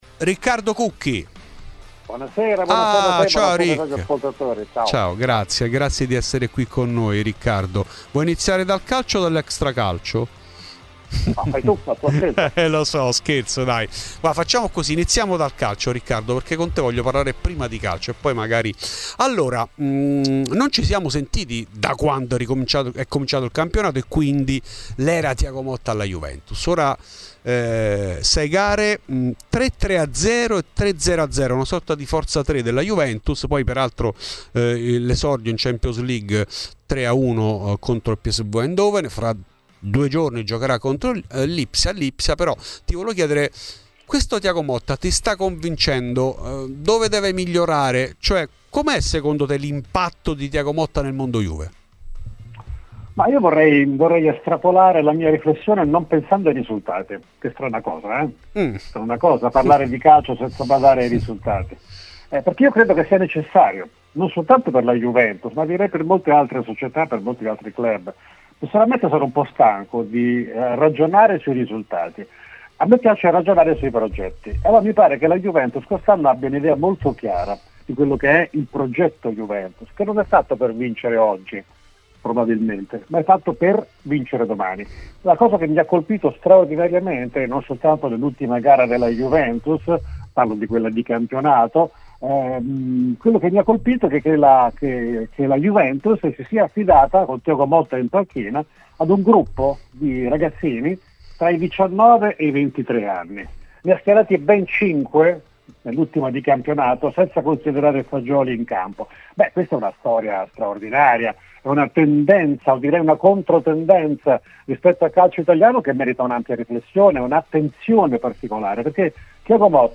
In ESCLUSIVA a Fuori di Juve Riccardo Cucchi, storica voce di Tutto il calcio minuto per minuto.